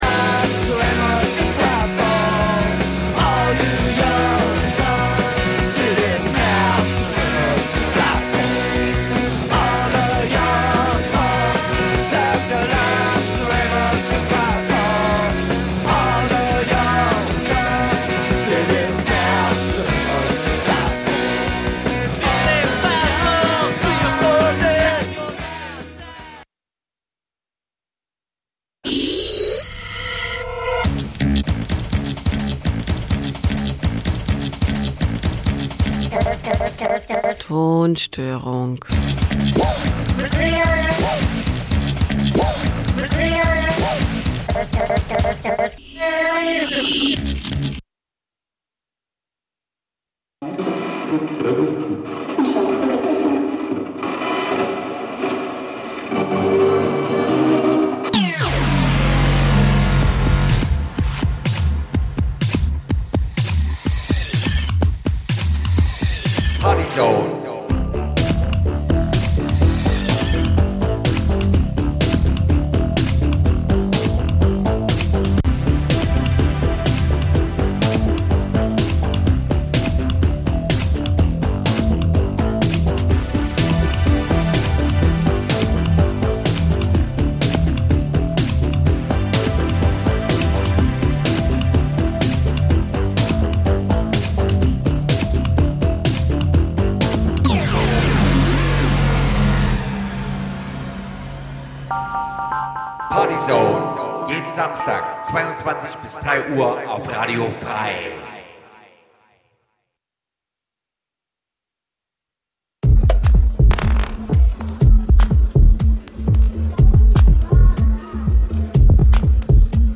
Elektronische Tanzmusik